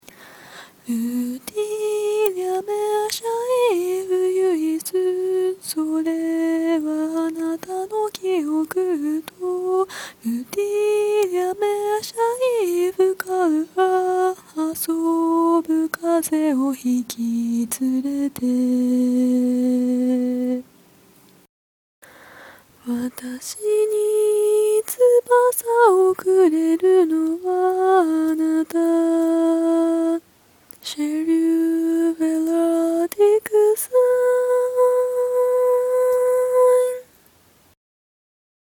勝手に歌っちゃいました